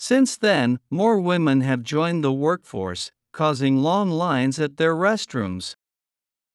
３）スロー（前半／後半の小休止あり）